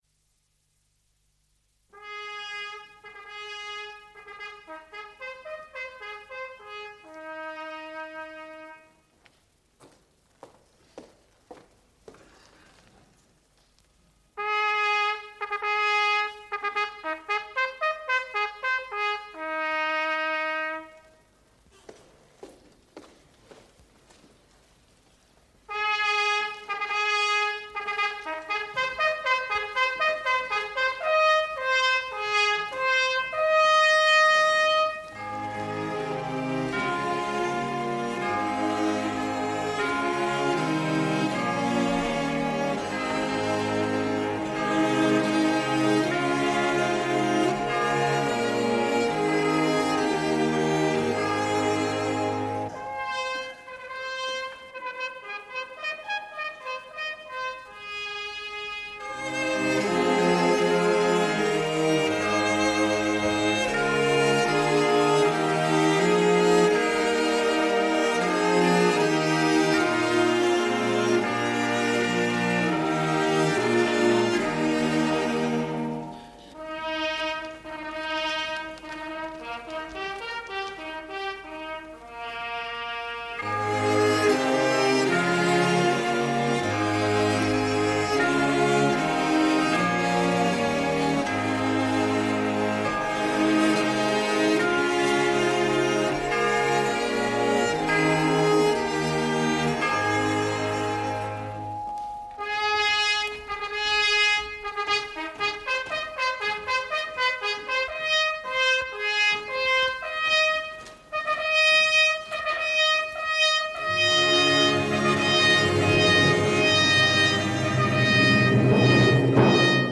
Komedie o umučení a slavném vzkříšení Pána a Spasitele našeho Ježíše Krista - Česká lidová - Audiokniha
• Čte: Václav Postránecký, Josef Karlík,…